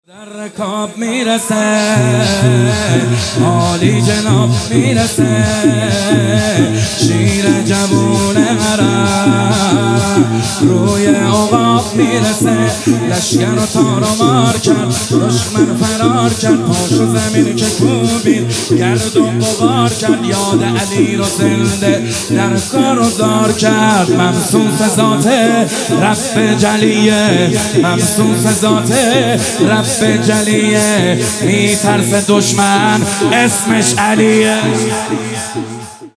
مداحی
هیئت عشاق الرضا (ع) تهران